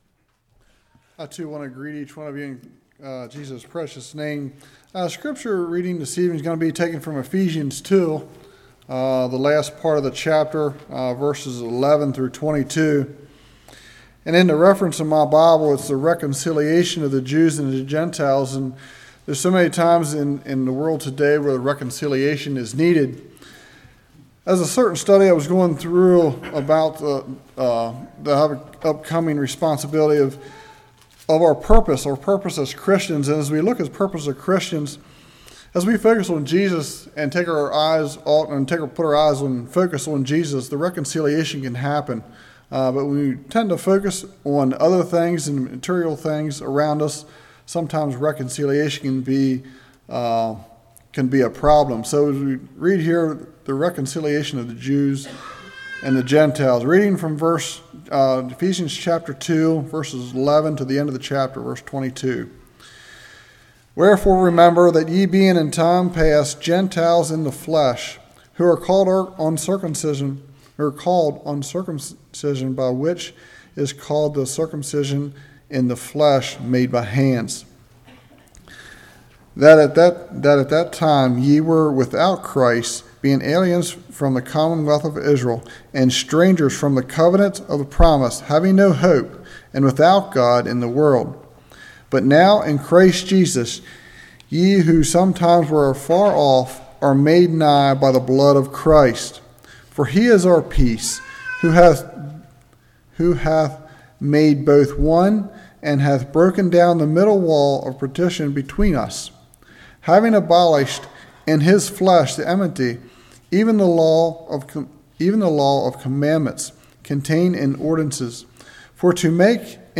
Ephesians 2:11-22 Service Type: Evening What kinds of stones are we?